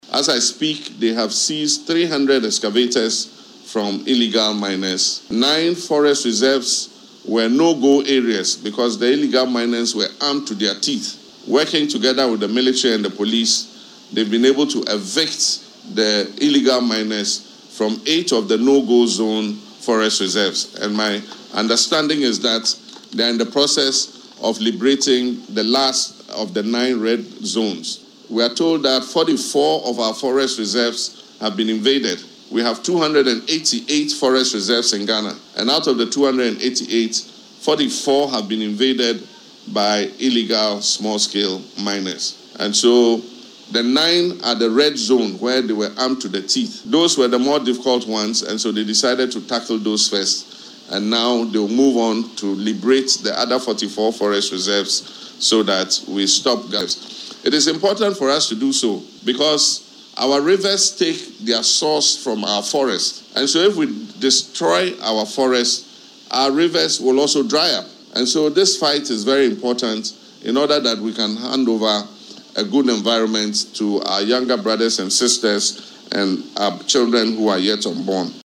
Speaking during World Environment Day celebrations at Kwabenya, Accra, the President explained that the move aims to preserve the country’s trees and promote the use of sustainable materials such as recycled plastic and metal.